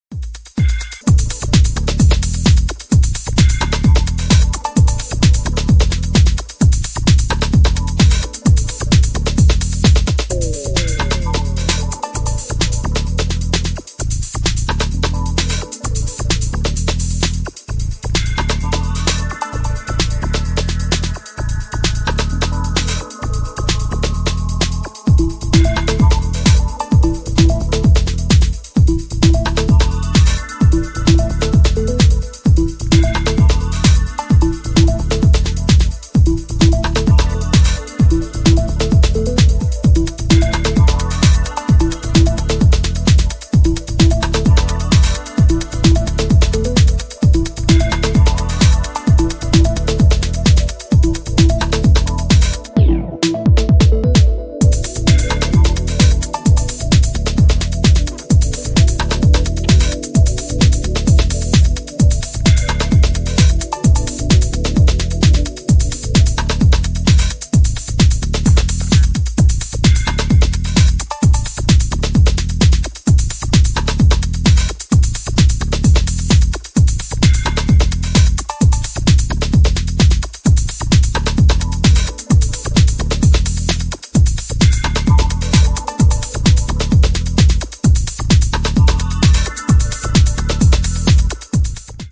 ジャンル(スタイル) MINIMAL / TECH HOUSE / DEEP HOUSE